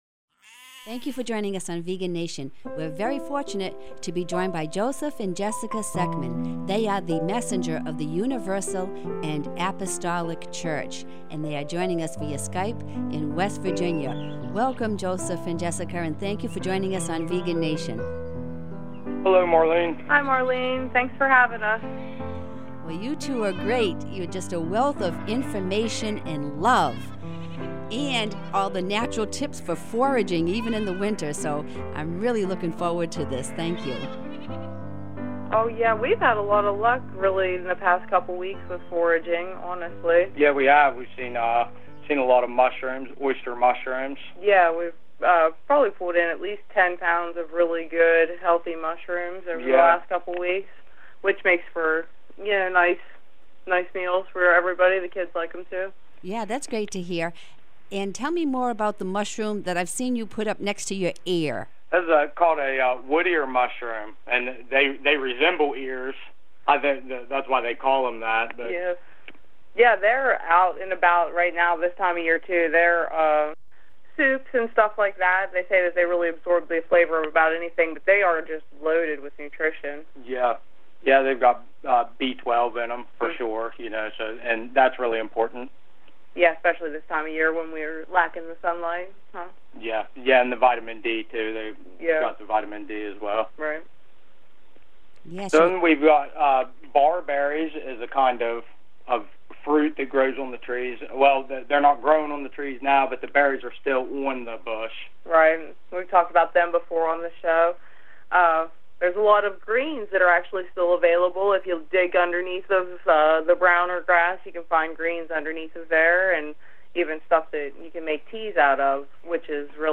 They join us from the woods in West Virginia, with lots of natural foraging tips too!